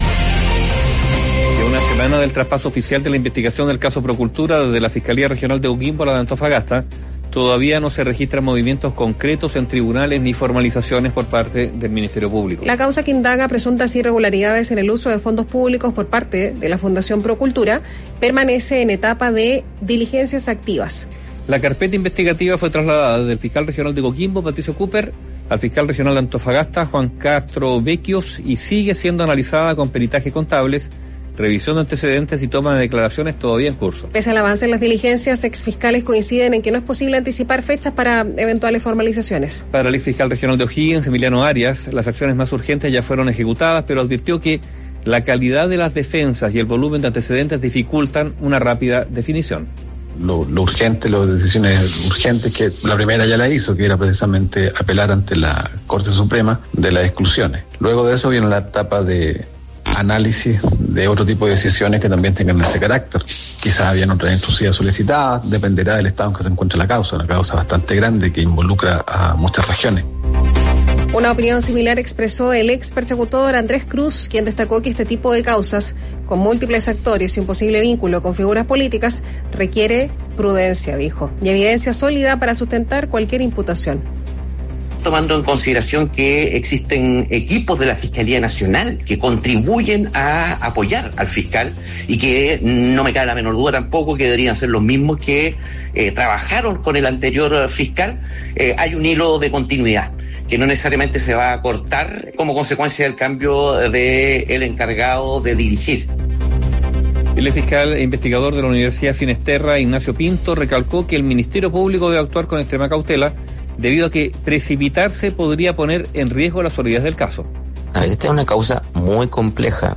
Puede oír la nota emitida por el noticiero matinal de Radio Bíobio, a continuación.